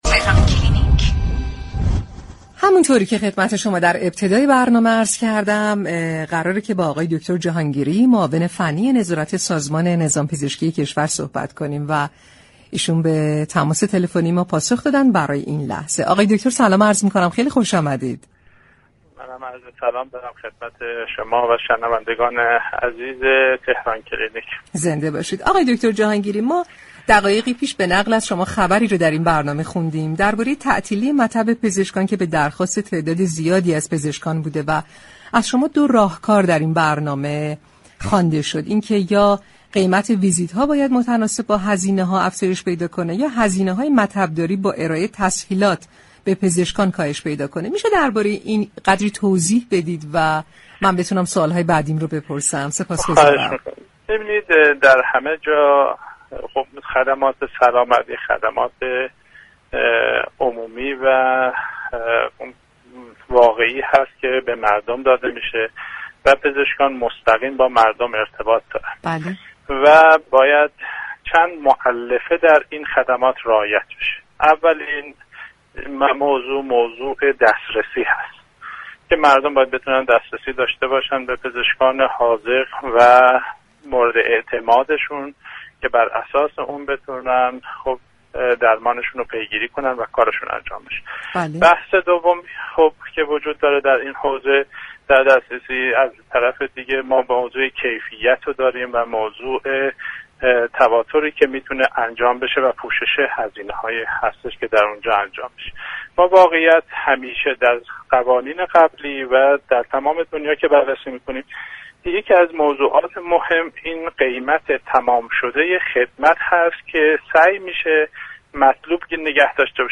وی در گفتگوی تلفنی با تهران كلینیك رادیو تهران در این‌باره گفت: در همه جای دنیا خدمات سلامت بطور عمومی ارائه می‌شود و پزشكان با مردم ارتباط دارد و لازم است چند مؤلفه در این خدمات رعایت شود.